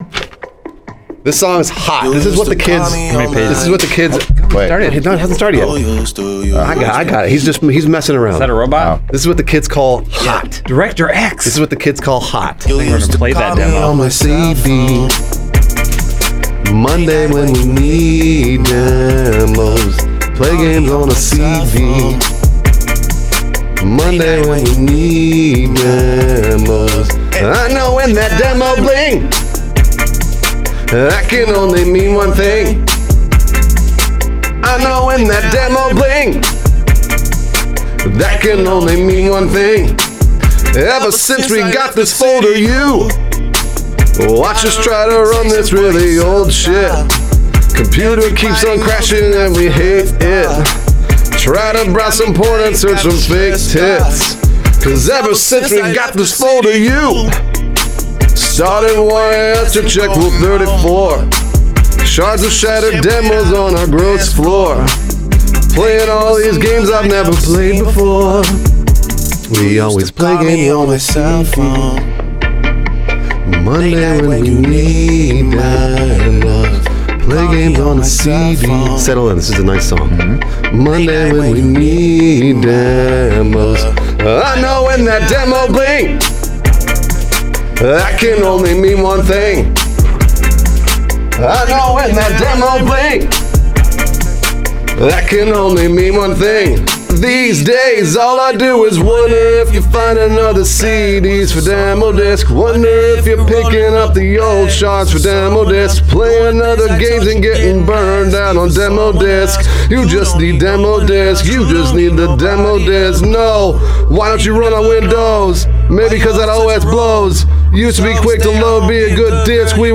BPM68-135
Audio QualityCut From Video